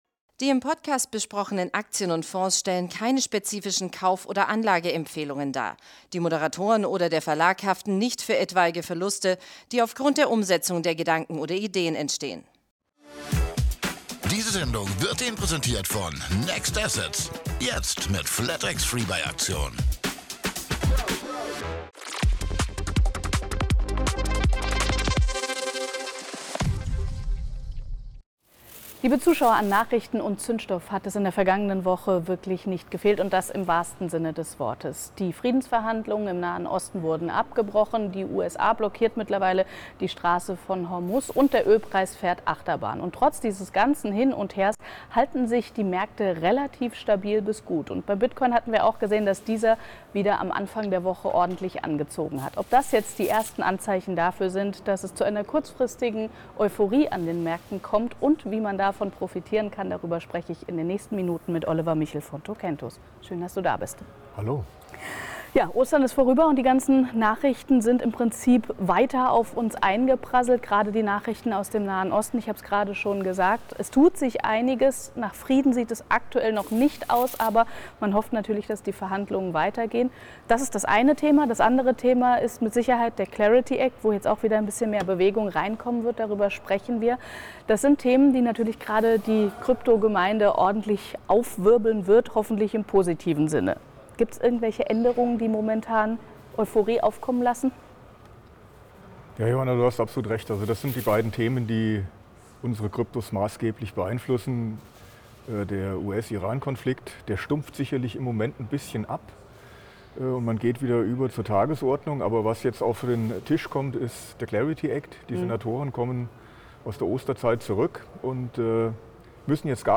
Das Gespräch wurde am 14.04.2026 an der Frankfurter Börse aufgezeichnet.